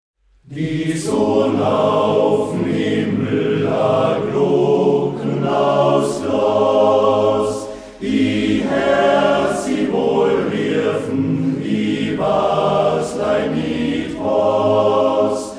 Aufgenommen im Oktober 2009 in der Volksschule